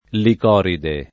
vai all'elenco alfabetico delle voci ingrandisci il carattere 100% rimpicciolisci il carattere stampa invia tramite posta elettronica codividi su Facebook Licoride [ lik 0 ride ] o Licori [ lik 0 ri ] pers. f. stor.